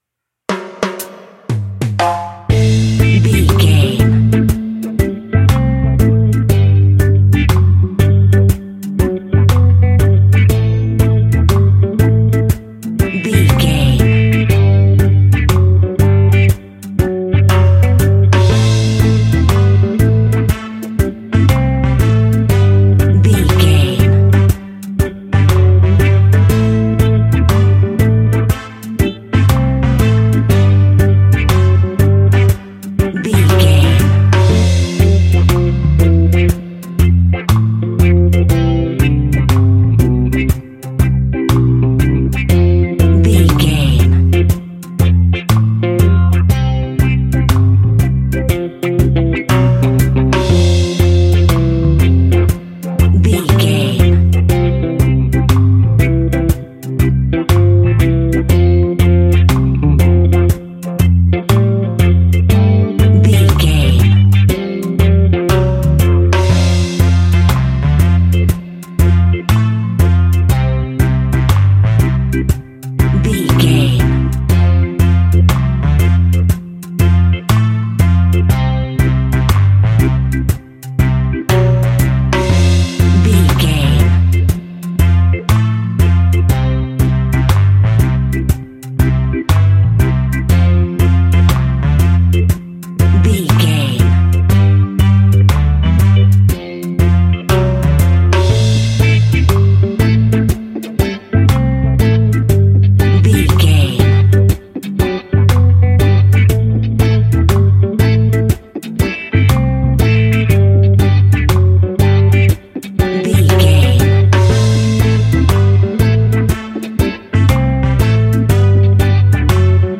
Classic reggae music with that skank bounce reggae feeling.
Aeolian/Minor
dub
laid back
off beat
drums
skank guitar
hammond organ
percussion
horns